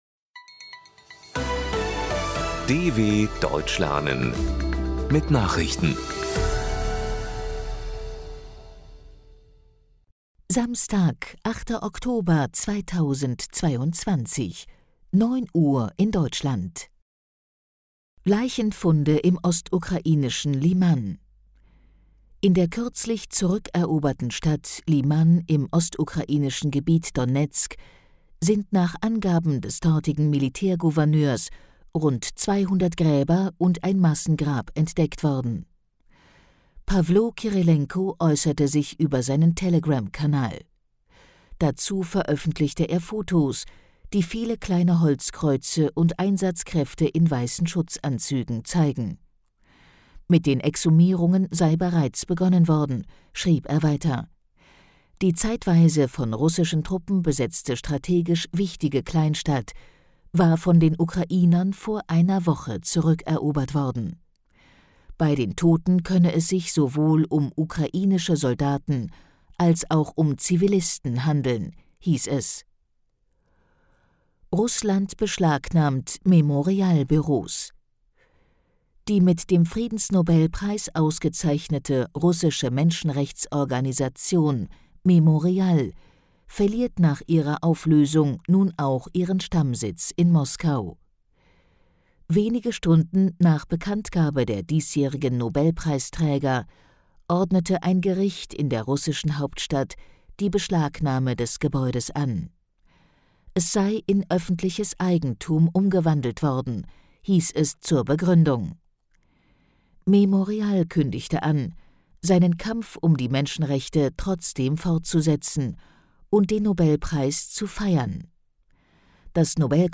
08.10.2022 – Langsam gesprochene Nachrichten
Trainiere dein Hörverstehen mit den Nachrichten der Deutschen Welle von Samstag – als Text und als verständlich gesprochene Audio-Datei.